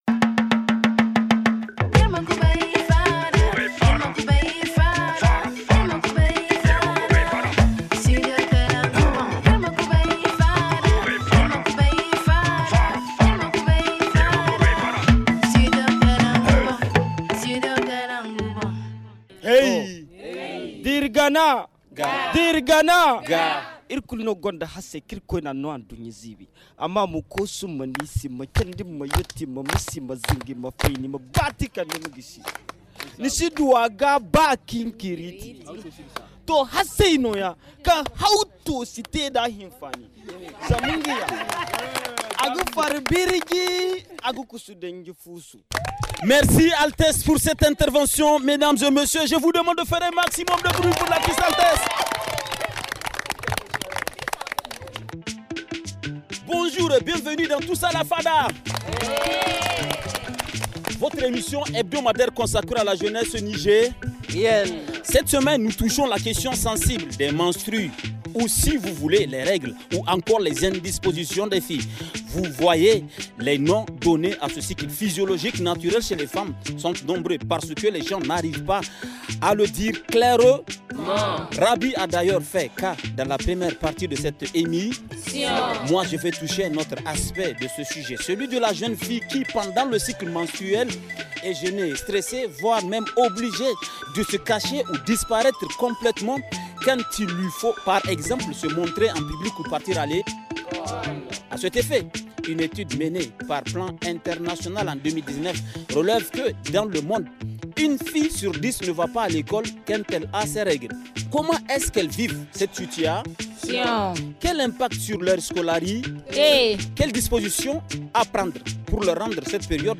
« Tous à la fada », c’est 35 minutes d’échanges avec des invités qui vont se présenter tout à l’heure. Nous vous servons en plus du thé, de la musique et une chronique qui a pour thème la mondialisation pour couronner le tout ! La fada est posée à l’institut supérieur de santé « ISS »